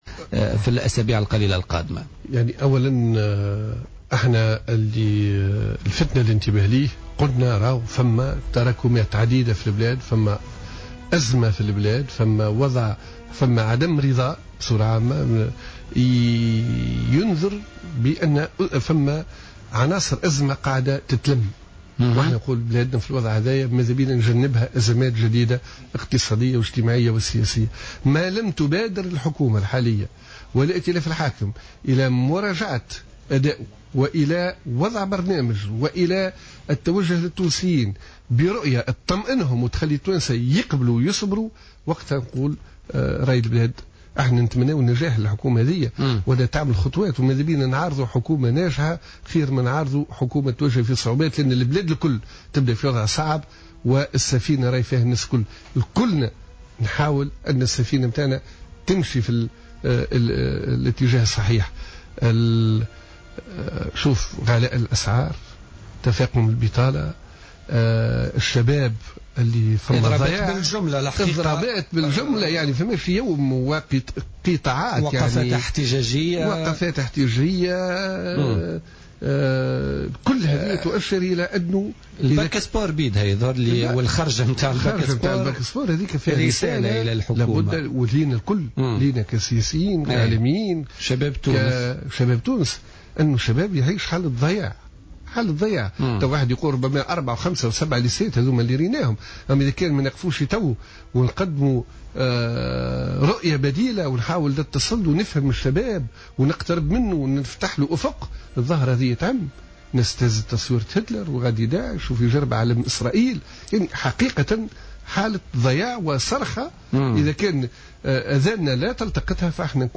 أكد الناطق الرسمي باسم الحزب الجمهوري عصام الشابي ضيف بوليتيكا اليوم الخميس 23 أفريل 2015 أن حكومة الصيد فاشلة ويجب أن تتدارك أمرها قبل فوات الاوان مؤكدا أن الوضع الراهن يشير إلى أن هناك عناصر أزمة بصدد التشكل على حد قوله.